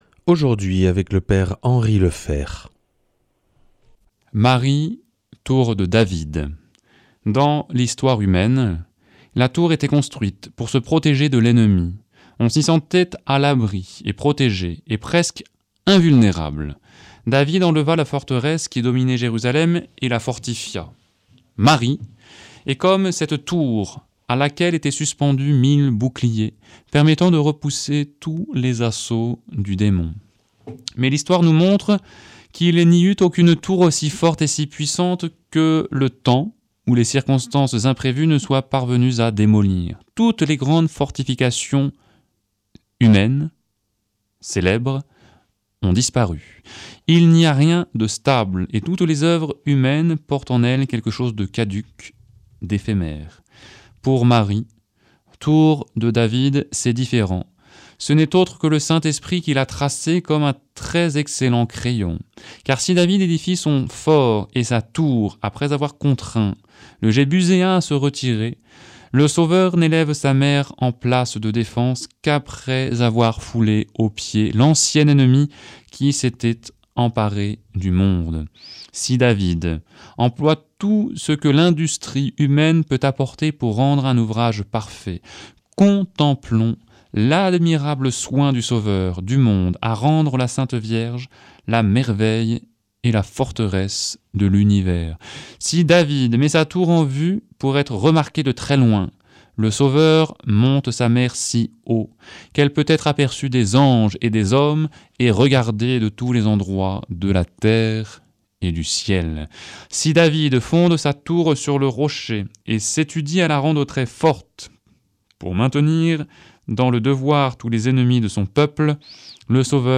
jeudi 29 mai 2025 Enseignement Marial Durée 10 min